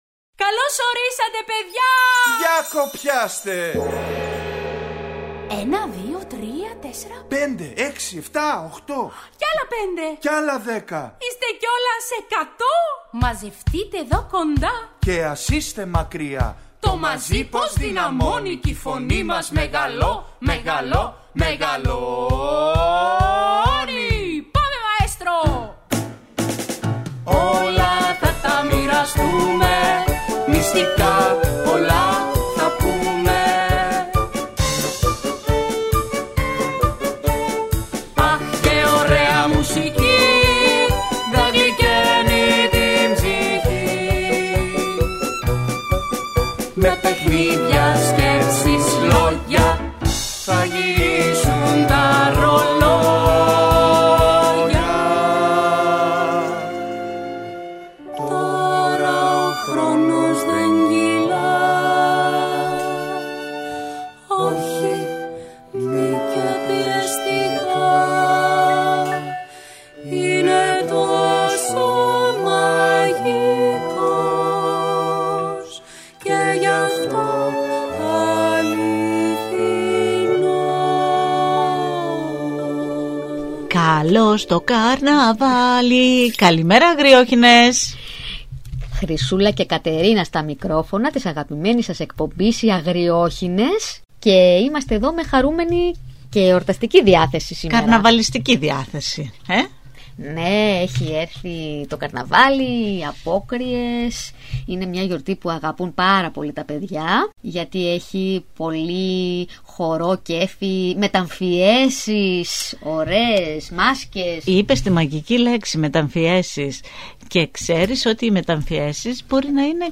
Ακούστε στην παιδική εκπομπή ‘’Οι Αγριόχηνες’’ το παραμύθι